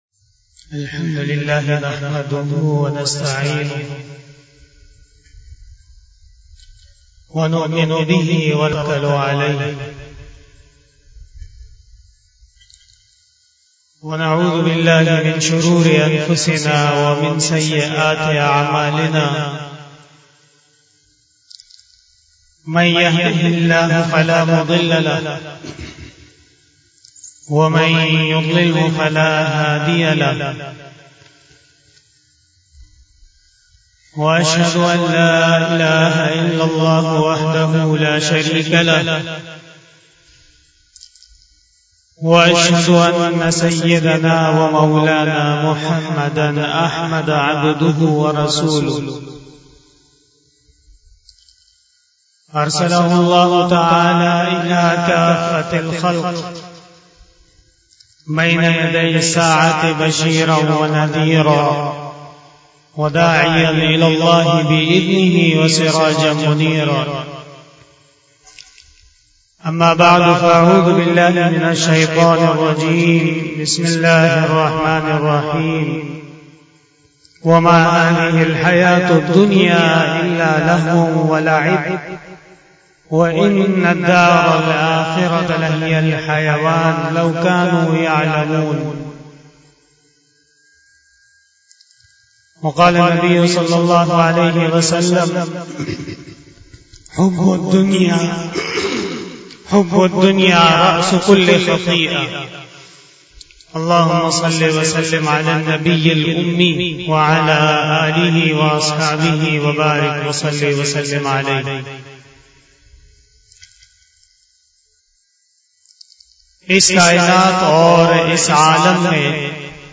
بیان جمعۃ المبارک
Khitab-e-Jummah